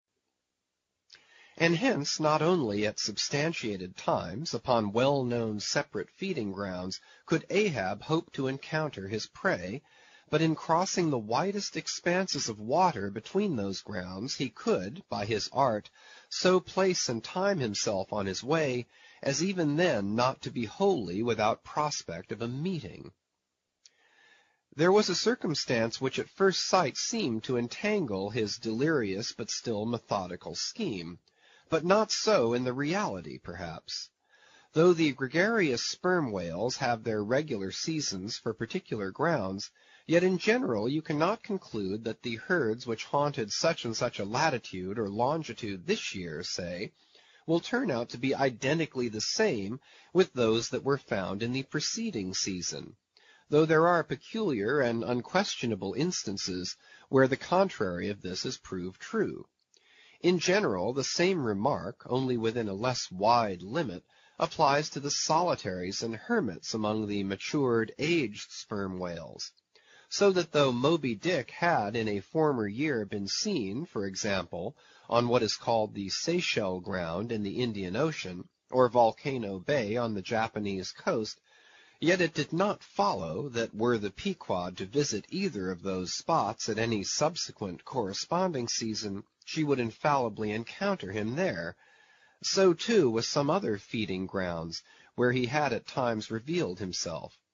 英语听书《白鲸记》第471期 听力文件下载—在线英语听力室